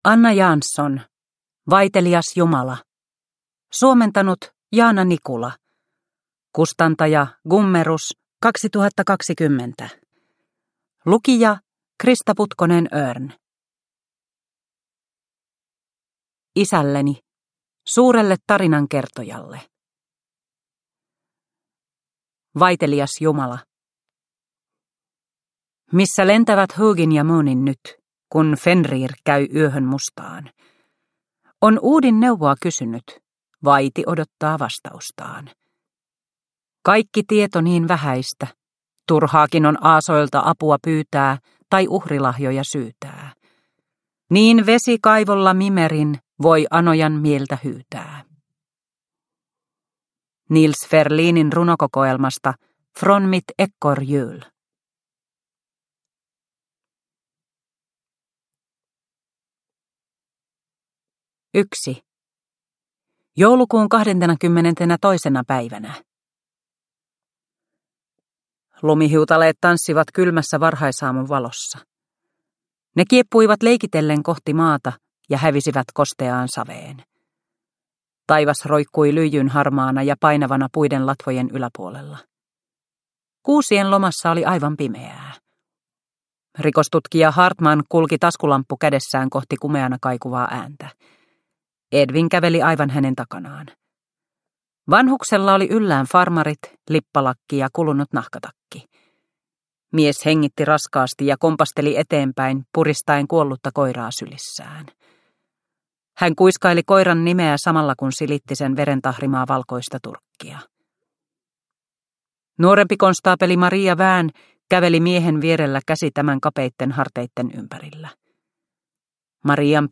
Vaitelias jumala – Ljudbok – Laddas ner